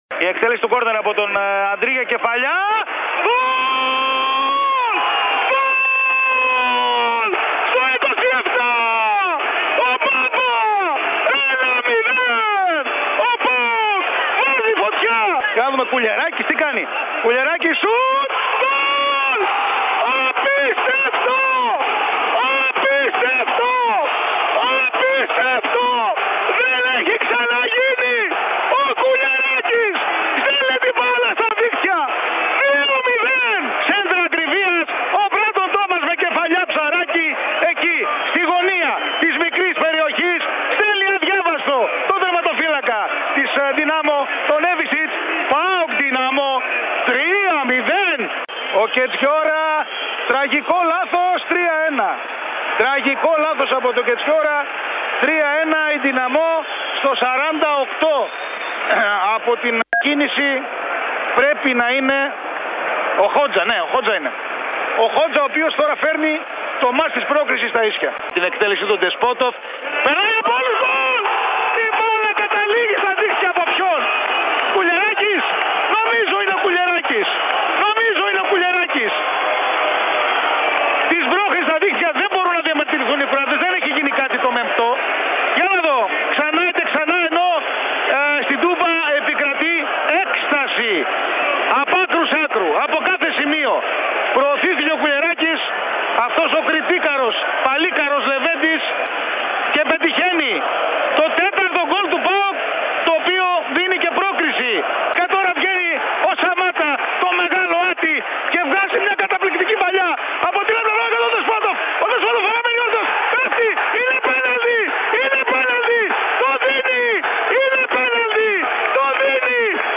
Europa Conference League: Η φοβερή περιγραφή των γκολ του ΠΑΟΚ στον Real fm 97,8